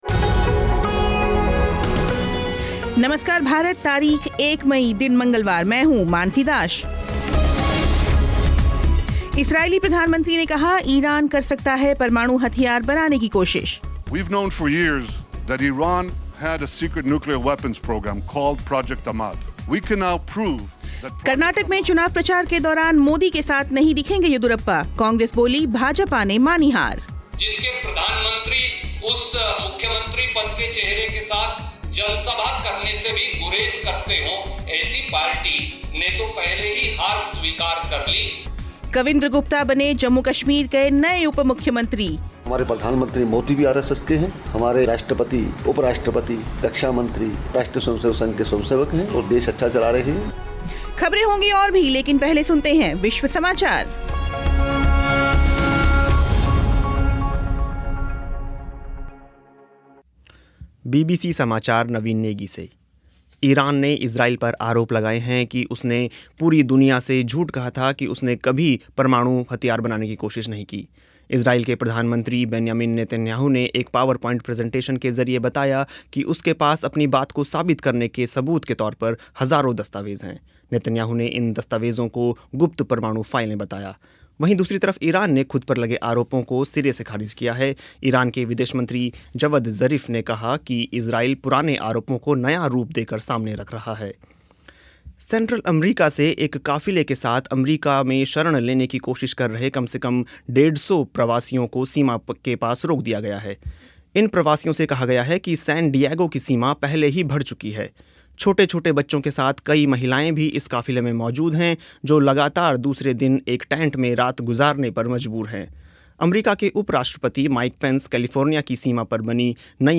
जम्मू कश्मीर के नए उप मुख्यमंत्री कविंद्र गुप्ता का साक्षात्कार